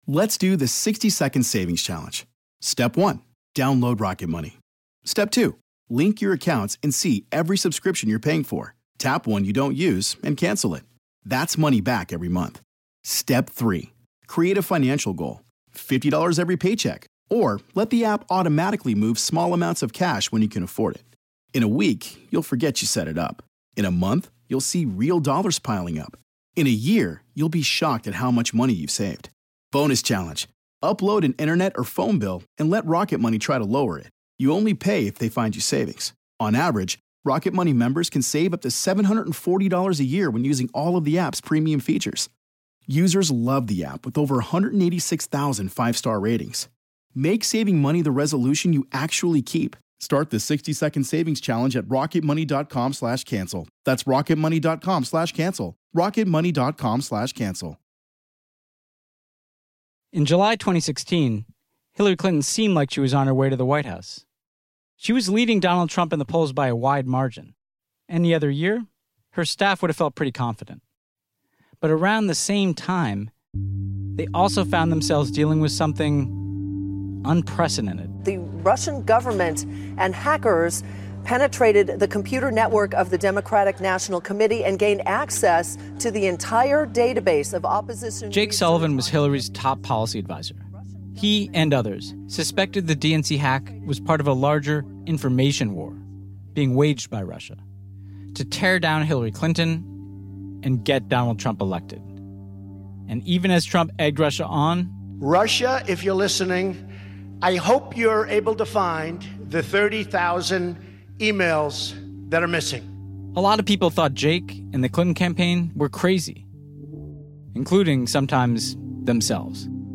We look at Myanmar, where hate campaigns on Facebook helped fuel ethnic cleansing. Host Ben Rhodes talks to the tech watchers in Southeast Asia who spotted disinformation and warned Facebook, and asks leading experts and activists about how to regulate Big Tech.